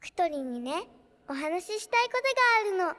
sukasuka-anime-vocal-dataset